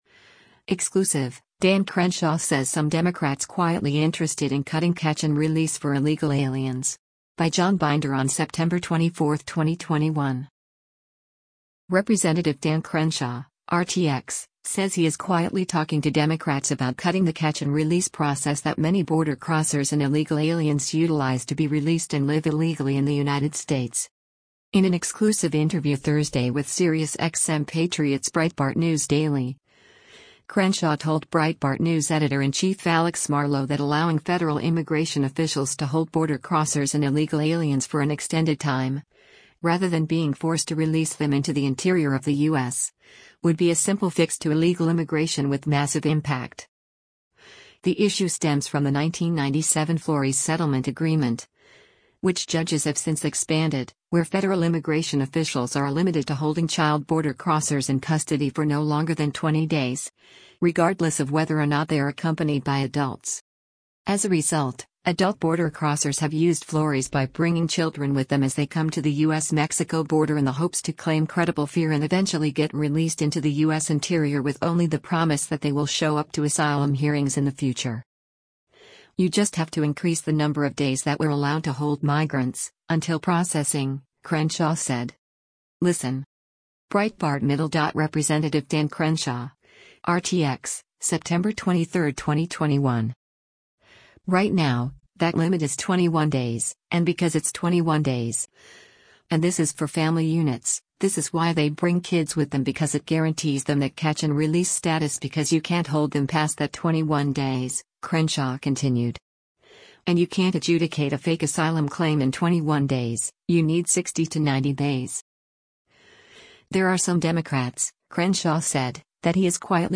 In an exclusive interview Thursday with SiriusXM Patriot’s Breitbart News Daily